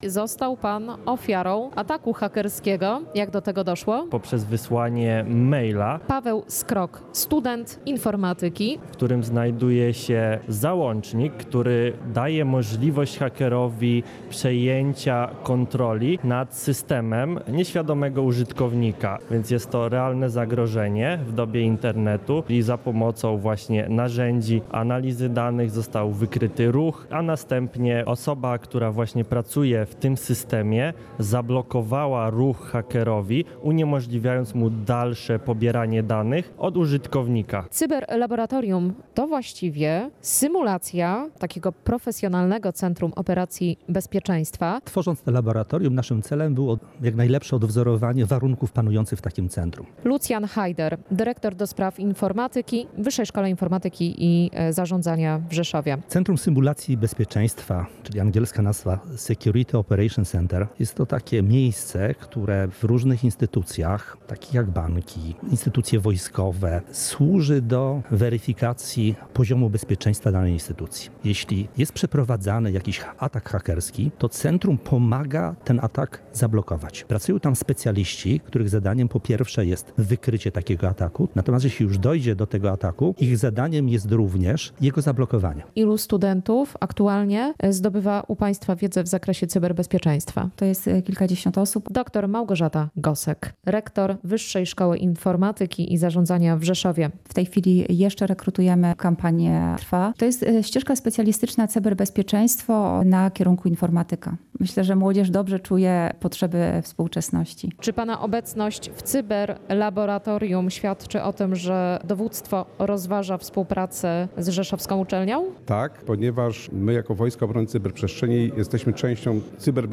Relacje reporterskie • Nowoczesne laboratorium cyberbezpieczeństwa otwarto w Rzeszowie.